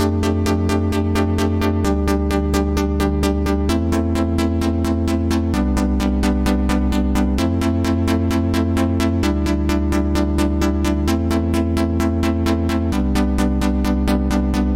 氢化合成器
标签： 130 bpm House Loops Synth Loops 2.48 MB wav Key : Unknown
声道立体声